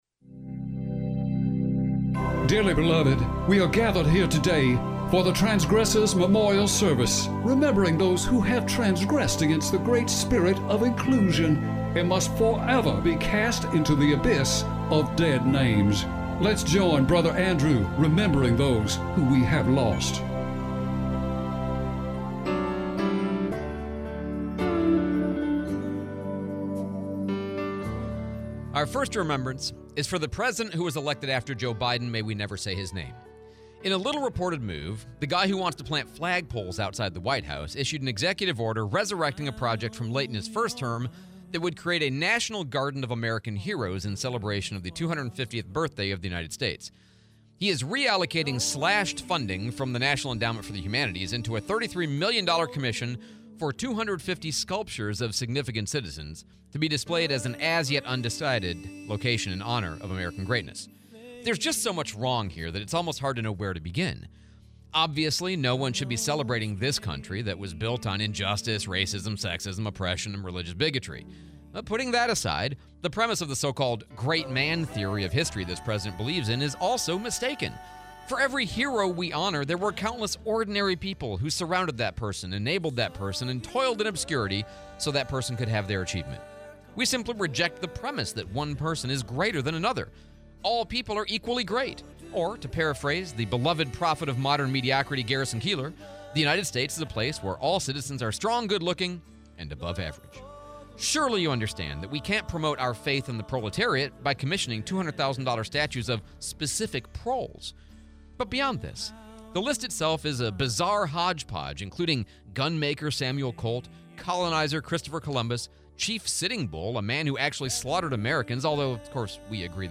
05/02/25 Transgressors Memorial Service